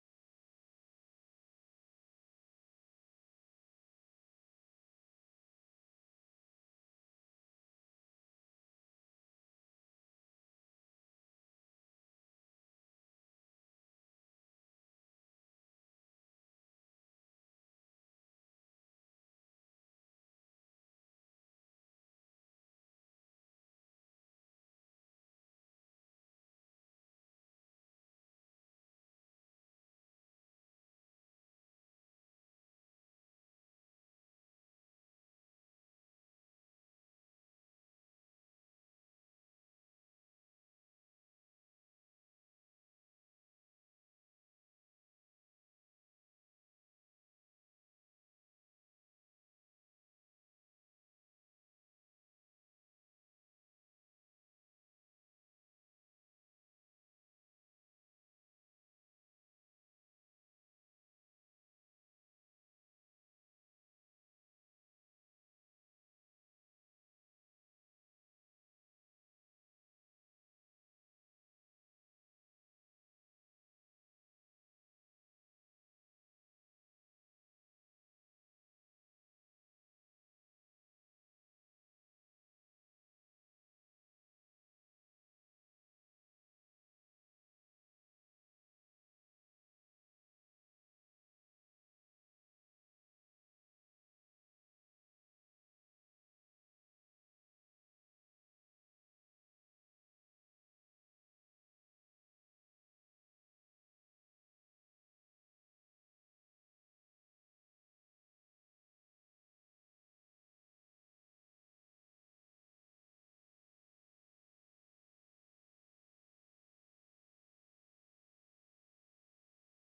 Seduta del consiglio comunale - 10.06.2025